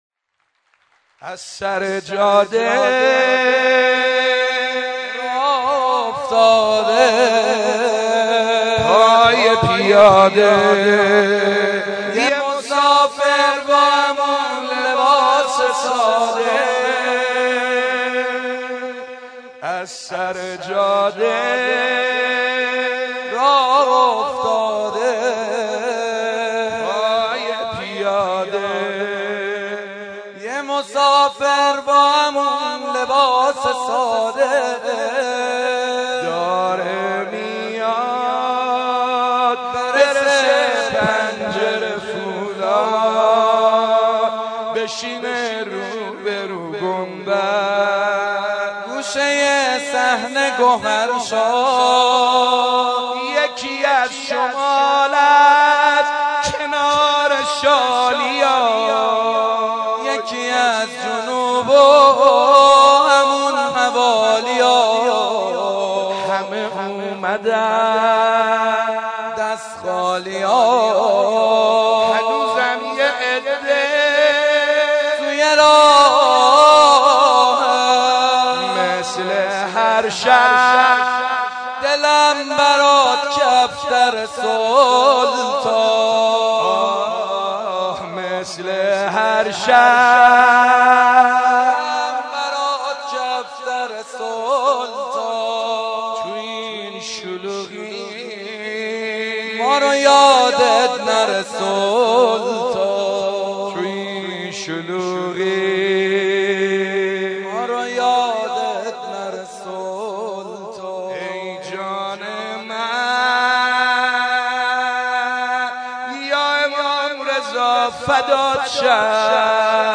صوت و تصاویر مولودی خوانی سعید حدادیان برای امام رضا(ع) - تسنیم
مراسم جشن میلاد امام رضا(ع)